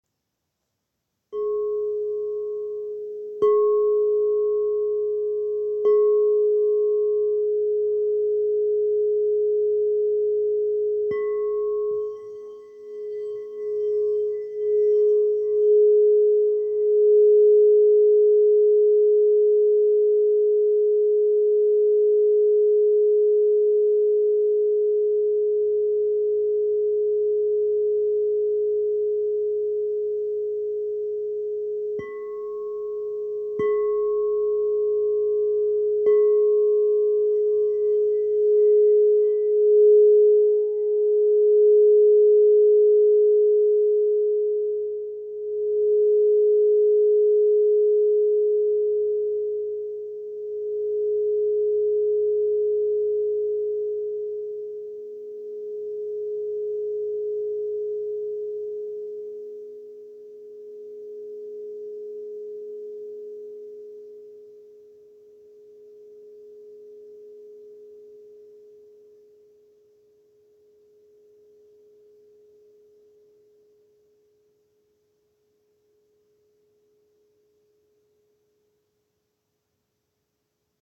"Zpívající" Křišťálové mísy
Mísa tón F velikost 9" (23cm)
Ukázka mísa F
Mísa F.m4a